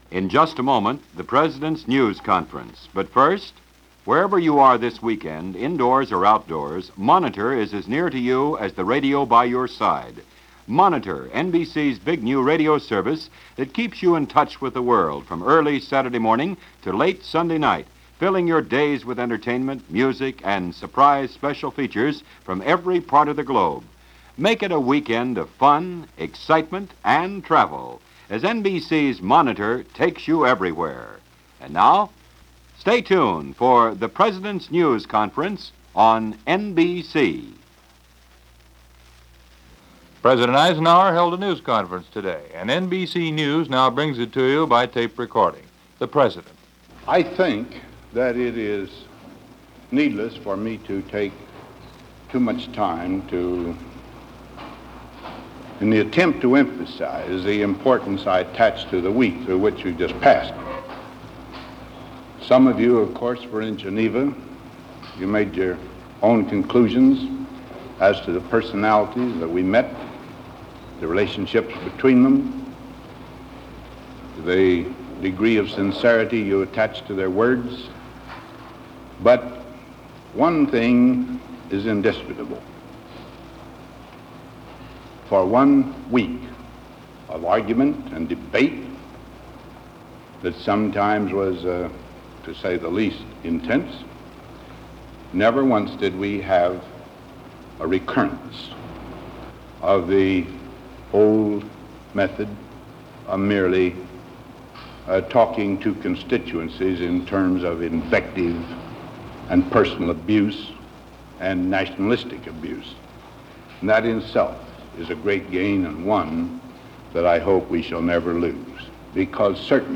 Press Conference #74 as presented by NBC Radio.
President Eisenhower, presiding over the 74th news conference of his presidency.
Ike-News-Conference-1955.mp3